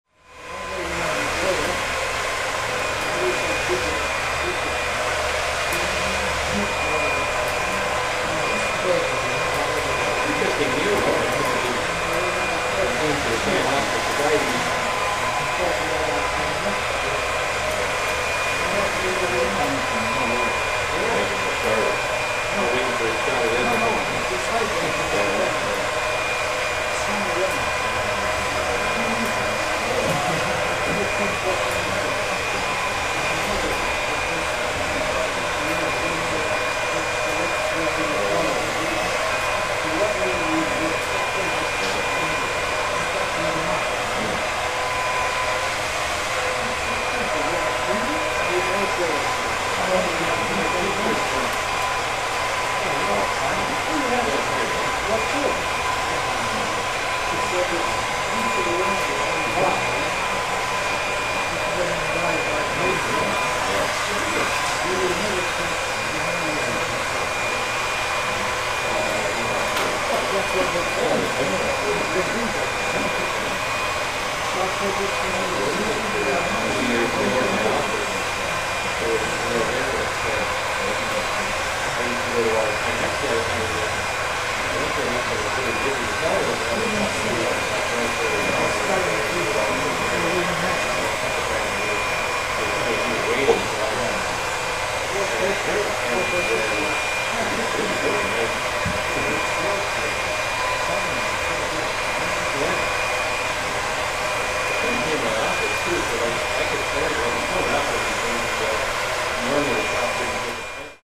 Звуки парикмахерской: фен, стрижка, разговоры и фоновая музыка